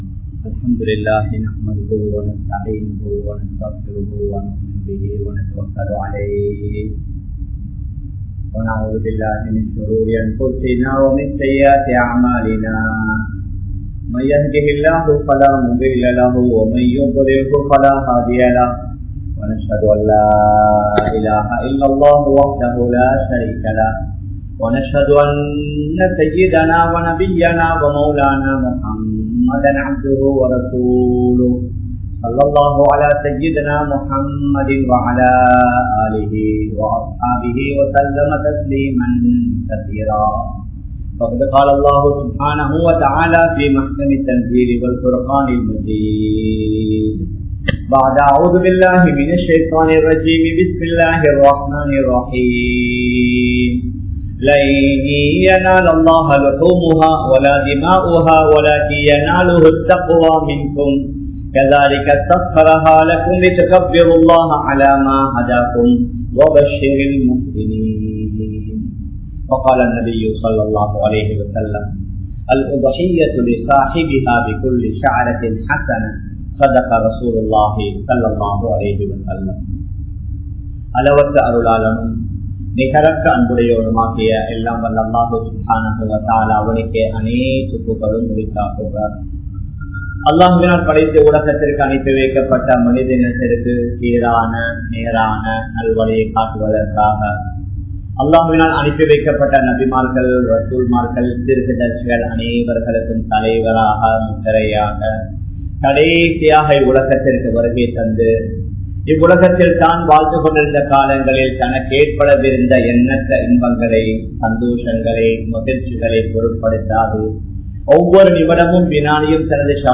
Rahasiyamaana Paavangalai Vittu Vidungal (ரகசியமான பாவங்களை விட்டு விடுங்கள்) | Audio Bayans | All Ceylon Muslim Youth Community | Addalaichenai
Colombo 03, Kollupitty Jumua Masjith